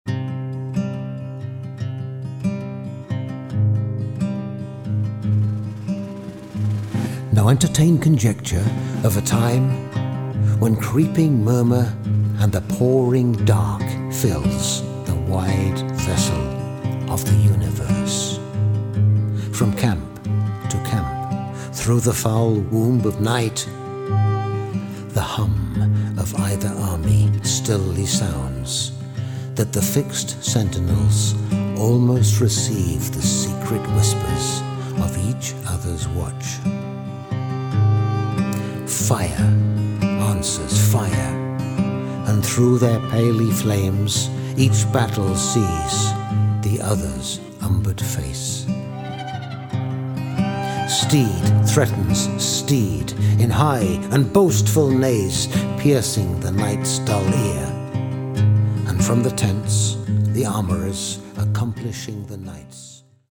accompanied by music and atmospheric arrangements.